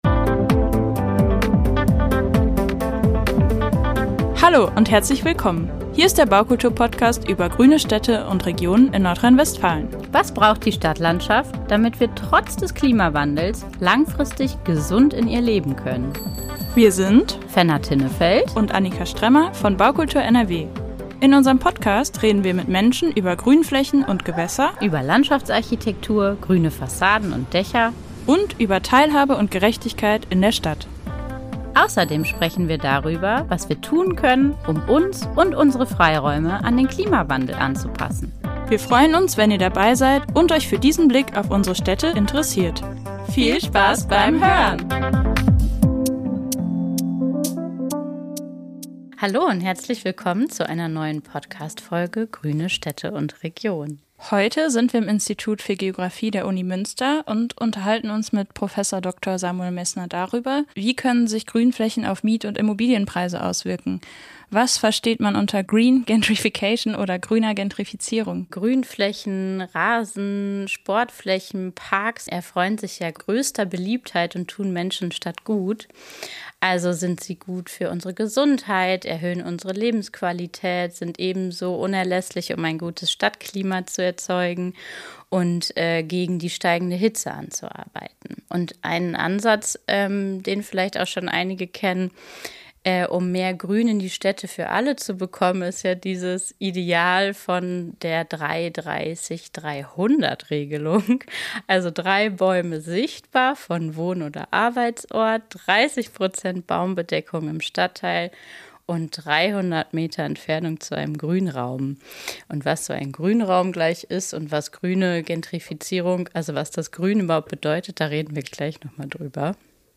Ein Gespräch